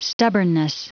Prononciation du mot stubbornness en anglais (fichier audio)
Prononciation du mot : stubbornness